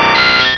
sovereignx/sound/direct_sound_samples/cries/pinsir.aif at master
pinsir.aif